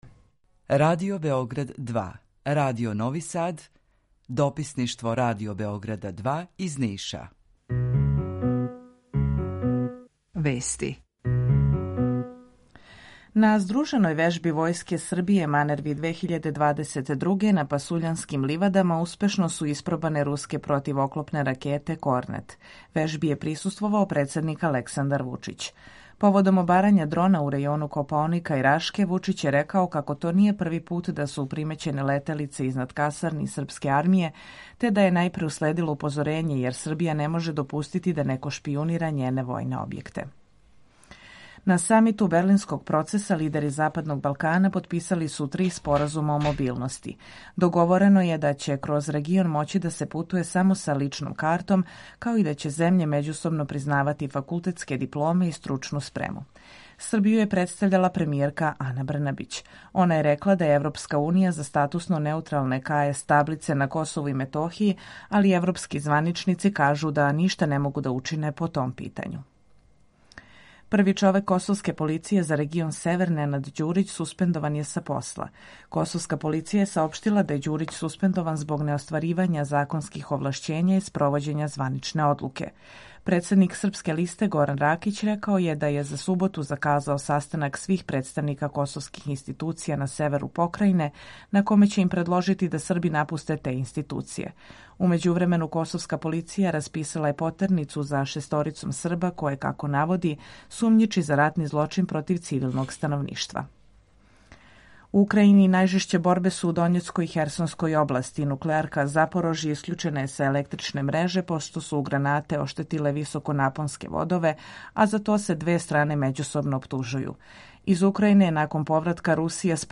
Емисију реализујемо уживо са Радиом Републике Српске у Бањалуци и Радиом Нови Сад.
У два сата, ту је и добра музика, другачија у односу на остале радио-станице.